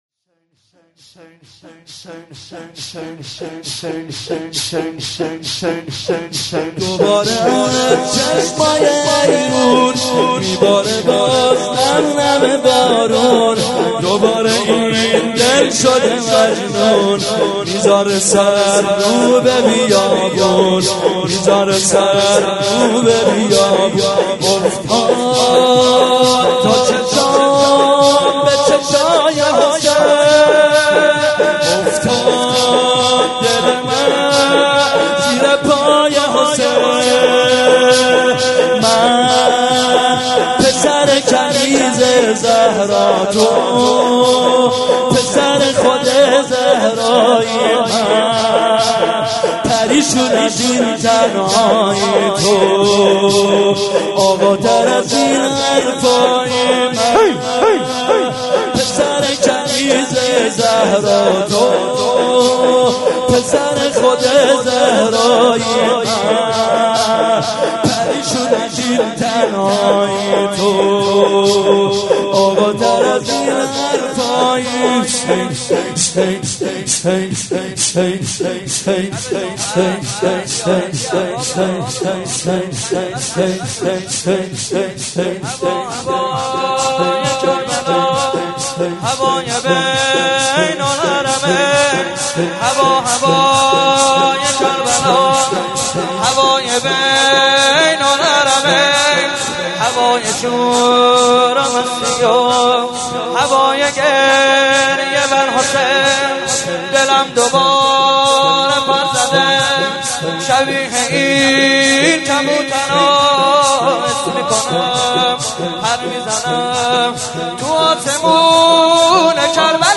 03.sineh zani.mp3